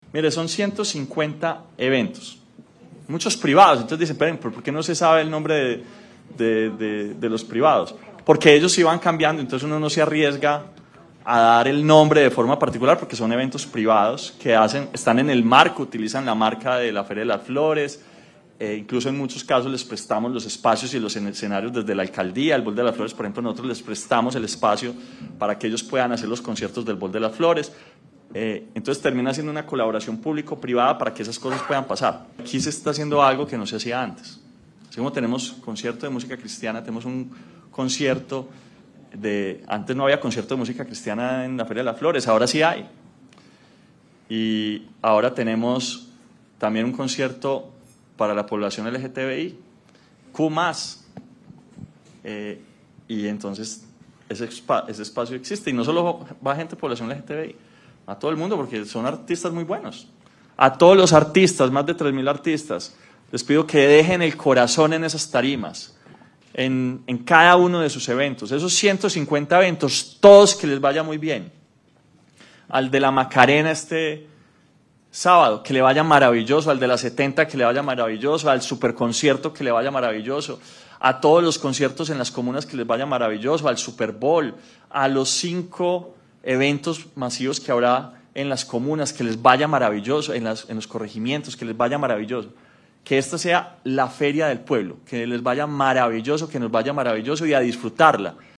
Palabras de Daniel Quintero Calle, alcalde de Medellín
Alcalde-Feria-de-las-Flores-01.mp3